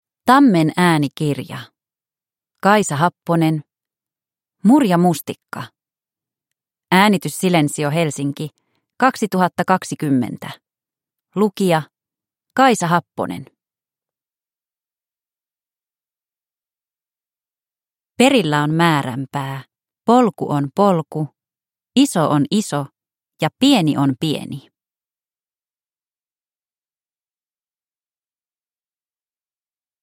Mur ja mustikka – Ljudbok – Laddas ner